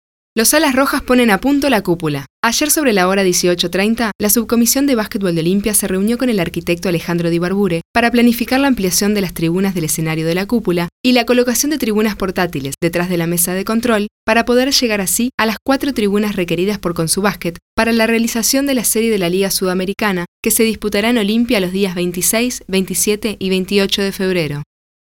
Spanish/English/Portuguese EspaĂ±ol rioplatense, espaĂ±ol uruguayo, espaĂ±ol neutro
Sprechprobe: eLearning (Muttersprache):
Noticias deportivas_0.mp3